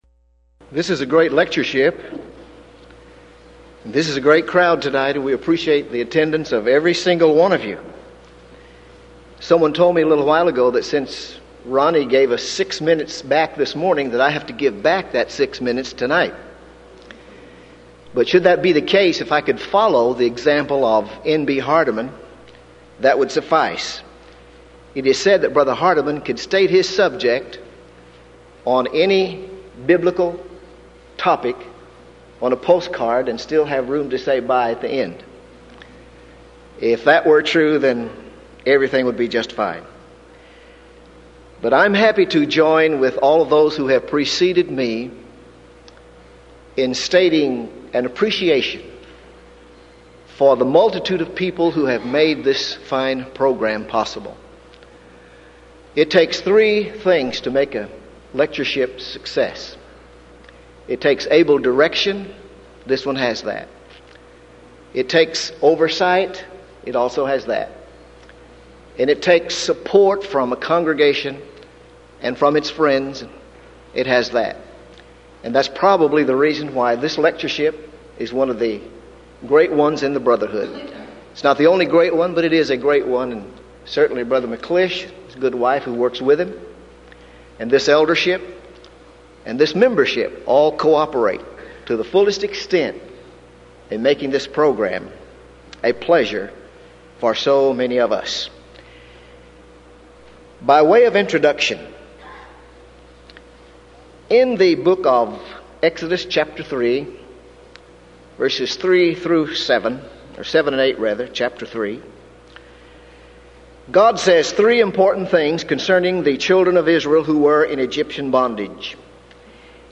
Event: 1994 Denton Lectures
lecture